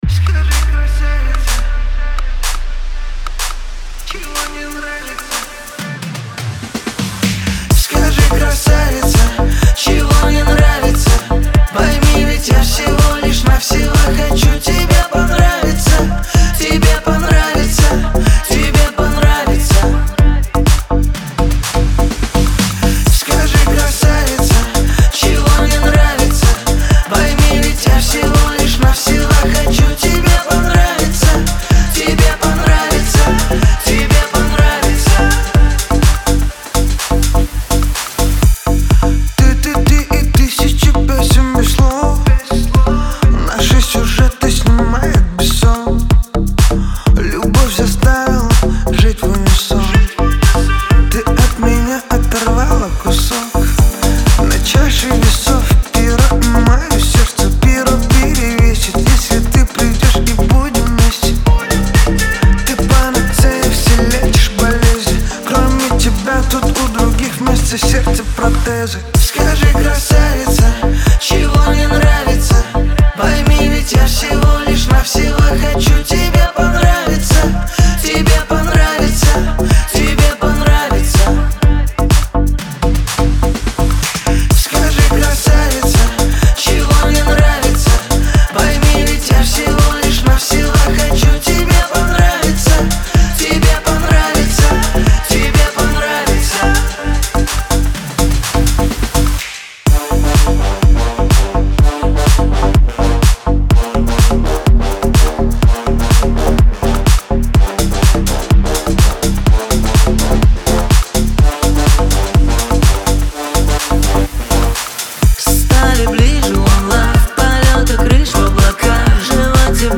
это динамичная композиция в жанре поп и EDM